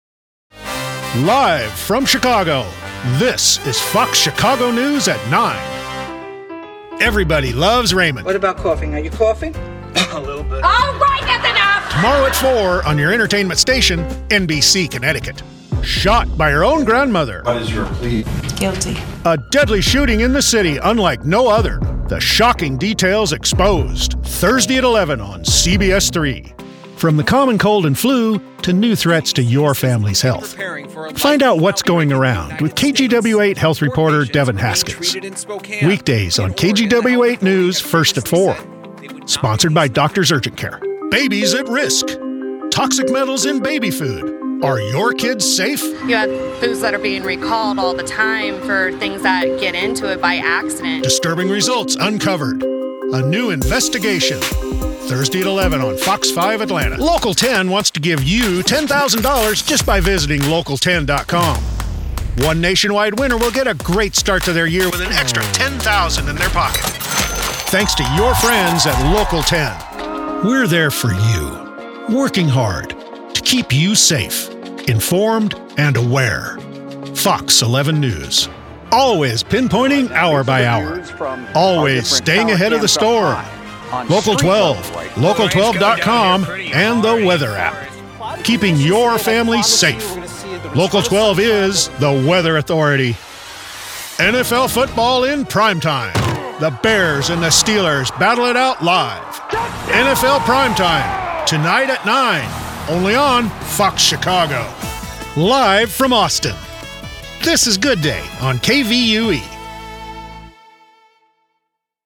Male
Radio / TV Imaging
T V Affiliate Imaging Demo
Words that describe my voice are Storyteller, Conversational, Relatable.